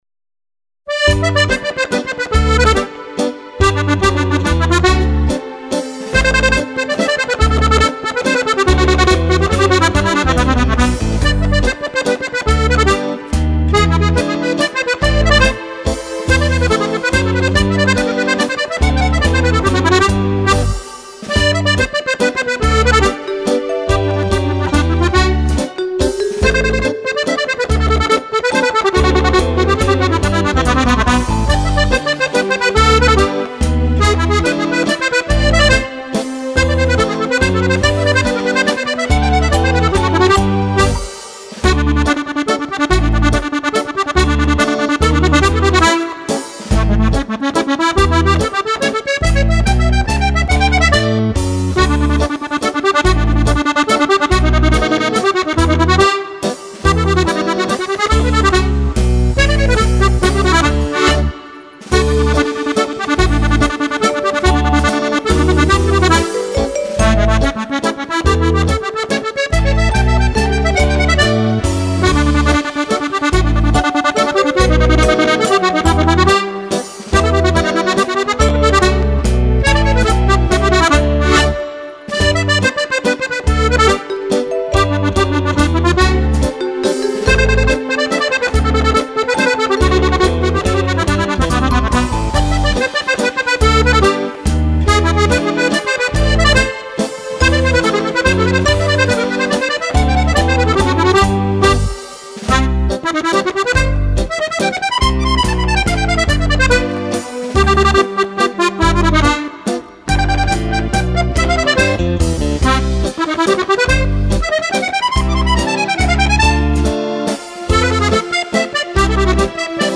Mazurka
Due ballabili per Fisarmonica